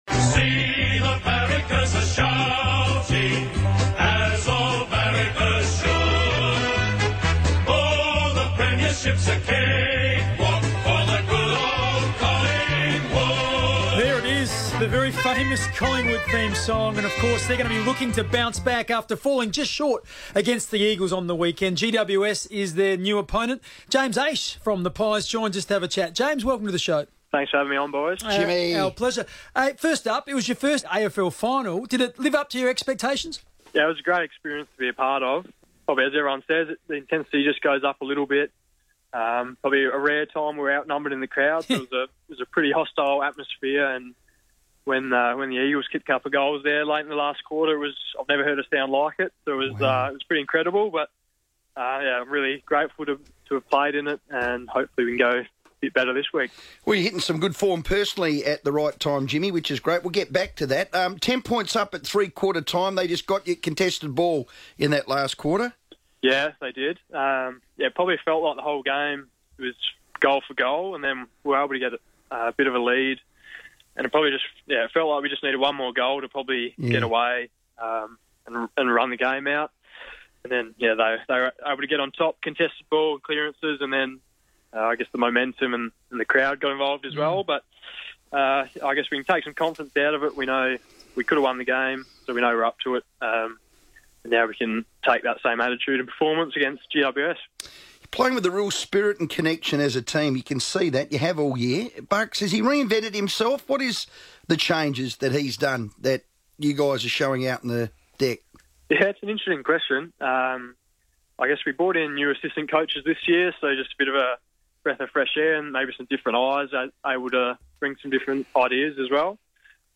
Radio: James Aish on FIVEaa
13 September 2018 | Listen to South Australian James Aish discuss Collingwood's finals campaign on FIVEaa radio in Adelaide.